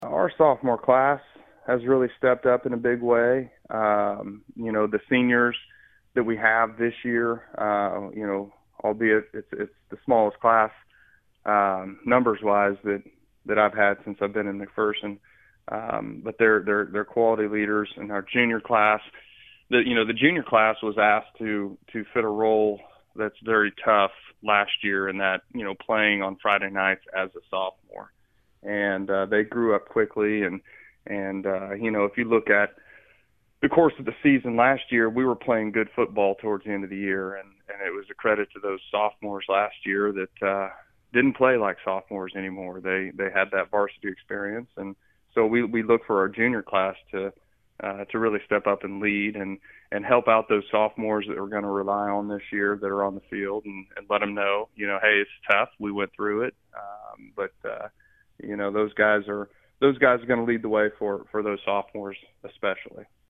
conducting the interview